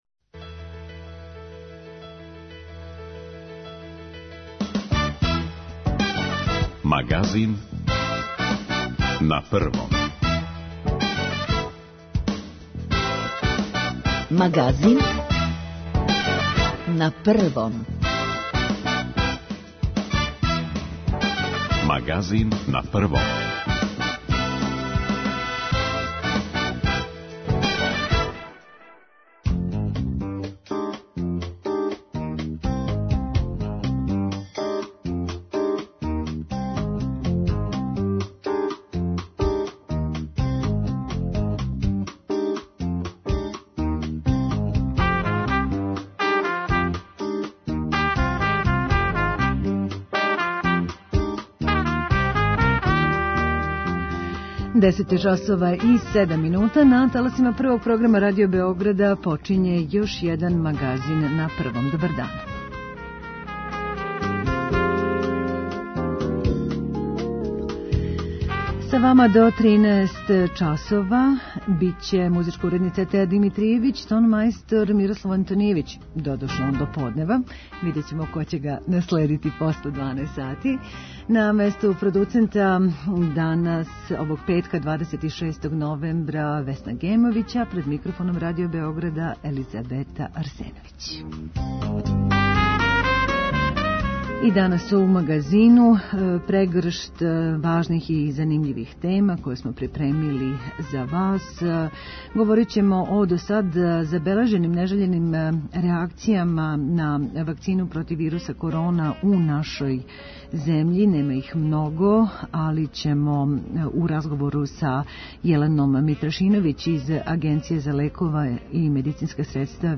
Говорићемо и о стању у Грчкој чији здравствени систем клеца пред новим налетом короне. преузми : 31.33 MB Магазин на Првом Autor: разни аутори Животне теме, атрактивни гости, добро расположење - анализа актуелних дешавања, вести из земље и света.